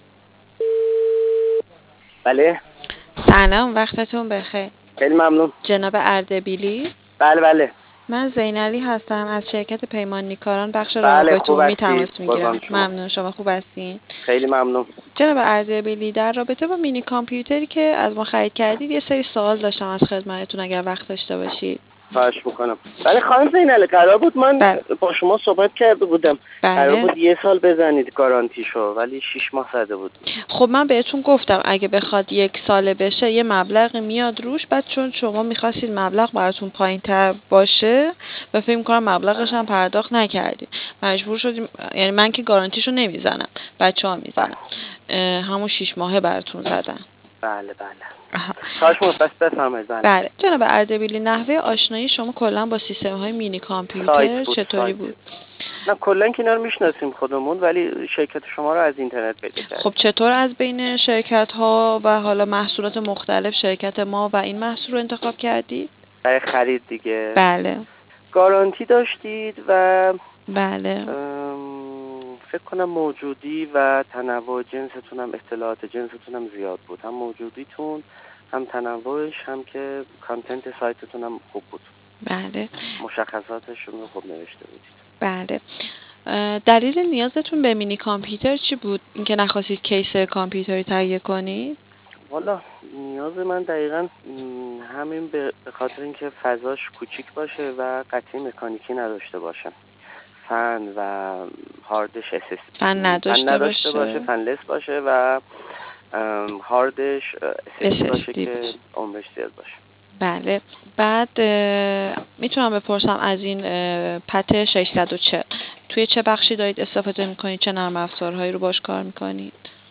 بدین منظور تعدادی مصاحبه با مشتریان عزیزمان که از مینی کامپیوتر استفاده کرده اند، گردآوری شده است.